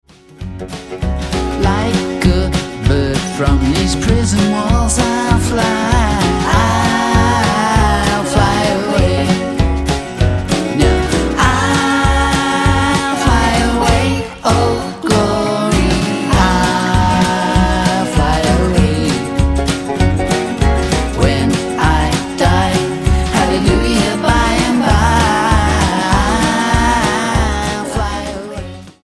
STYLE: Rock
wispy vocals are bolstered by layered background vocals